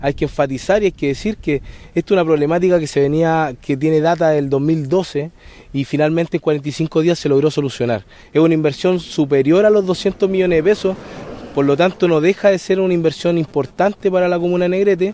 El gobernador de la Provincia de Bío Bío, Ignacio Fica, recordó que el problema está desde hace más de 6 años y que ahora se solucionó.